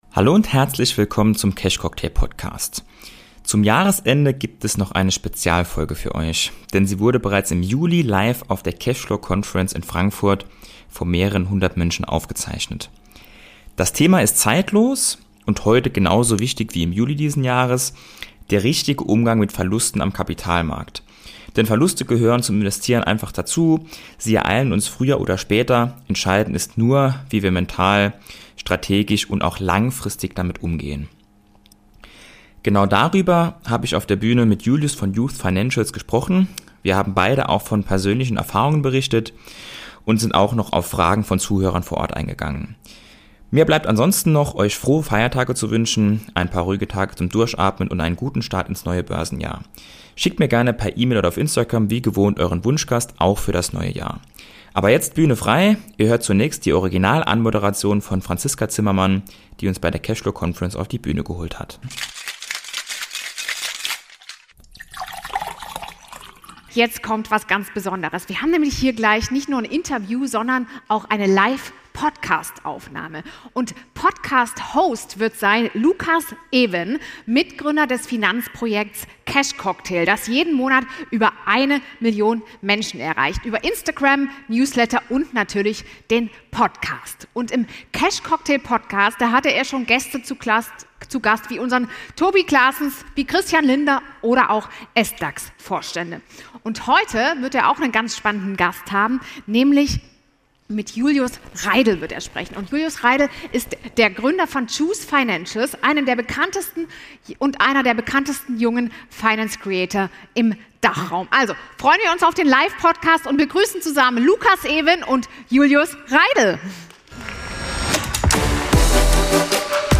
Live von der Cashflow Conference in Frankfurt